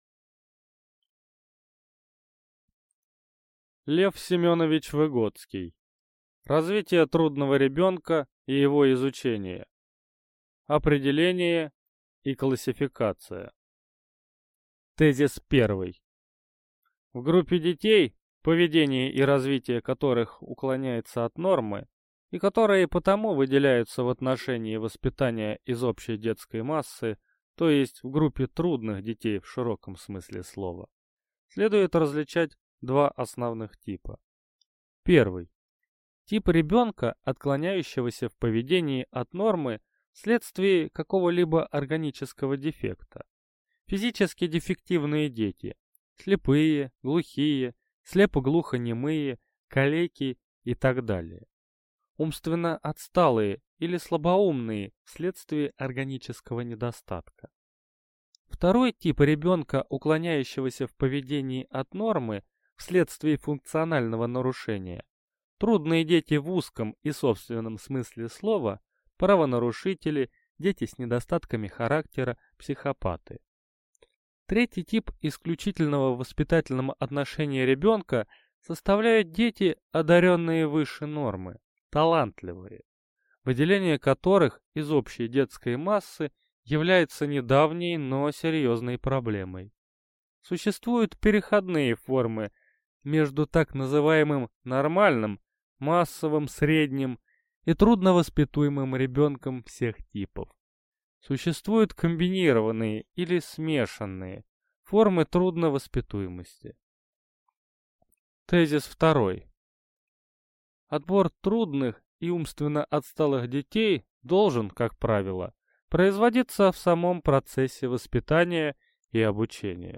Аудиокнига Развитие трудного ребенка и его изучение | Библиотека аудиокниг